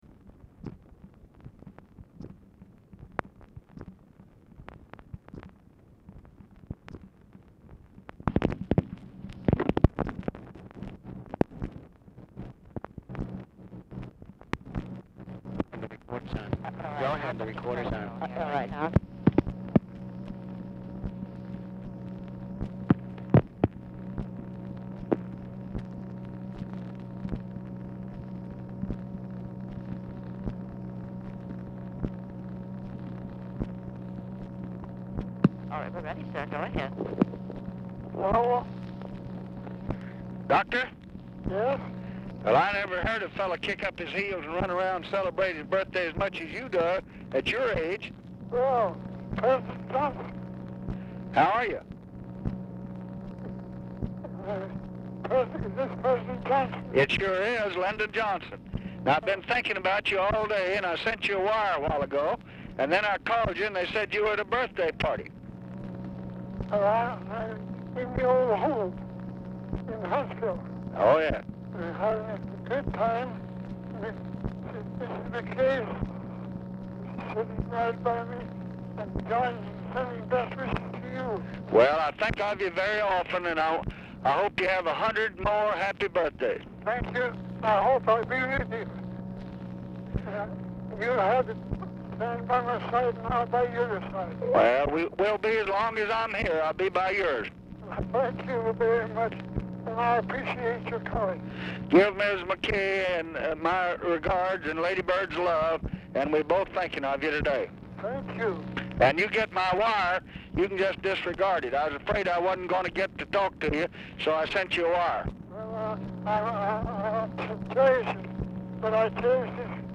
MCKAY ON HOLD 0:30; POOR SOUND QUALITY; BRIEF DISCUSSION BETWEEN UNIDENTIFIED MALE AND TELEPHONE OPERATOR ABOUT OPERATION OF RECORDER PRECEDES CALL
Format Dictation belt
Specific Item Type Telephone conversation Subject Condolences And Greetings Religion